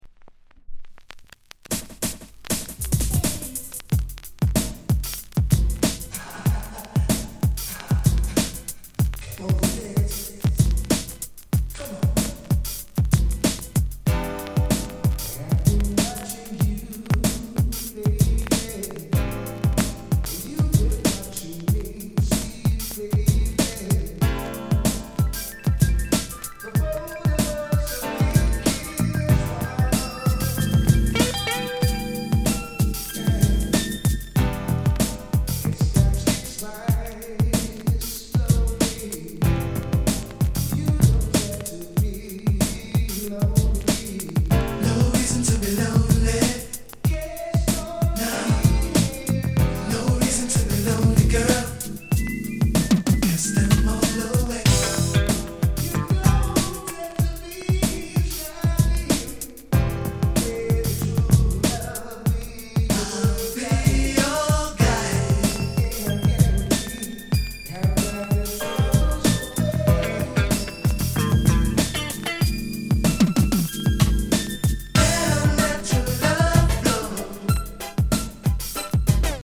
R&B スタイリー！
軽いチリ 乗りますが、気になるレベルではありません。